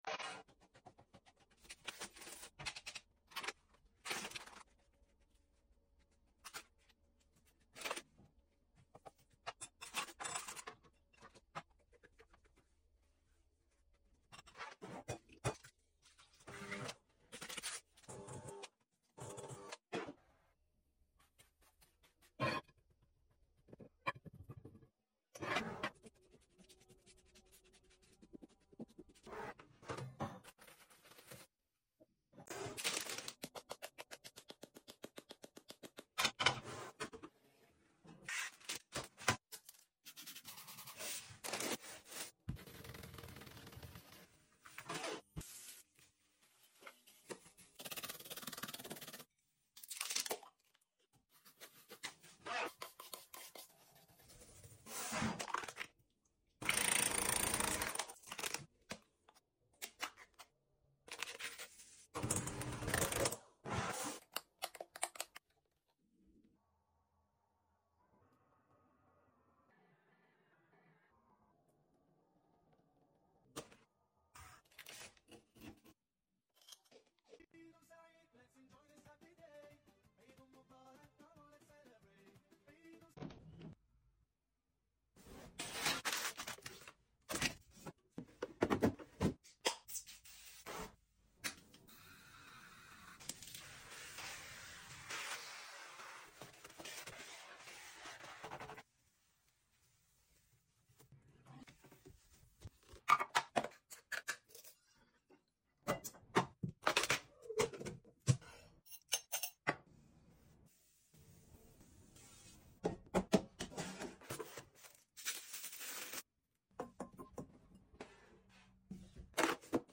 ASMR Cooking, Cleaning,Restocking And Some Sound Effects Free Download
ASMR cooking, cleaning,Restocking and some